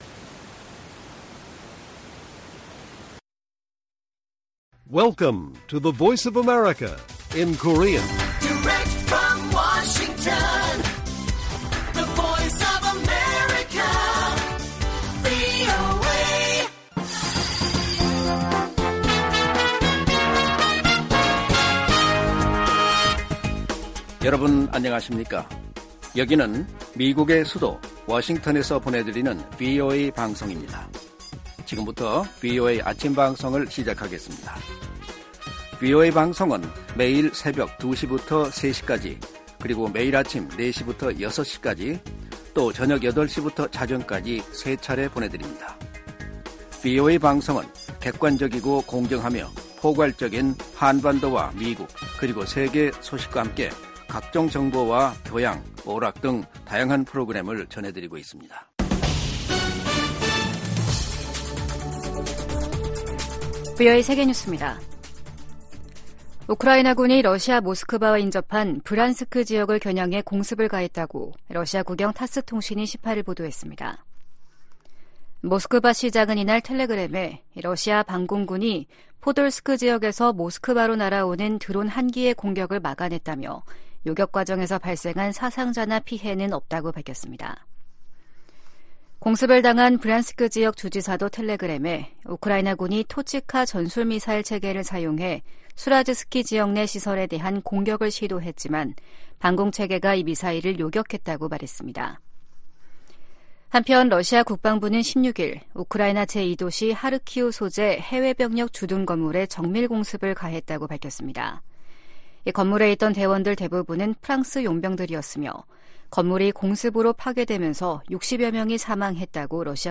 세계 뉴스와 함께 미국의 모든 것을 소개하는 '생방송 여기는 워싱턴입니다', 2024년 1월 19일 아침 방송입니다. '지구촌 오늘'에서는 파키스탄이 이란 내 목표물을 공습한 소식 전해드리고, '아메리카 나우'에서는 조 바이든 대통령과 의회 지도부가 우크라이나 지원을 위한 예산안 담판을 위해 회동했지만 입장차를 좁히지 못한 이야기 살펴보겠습니다.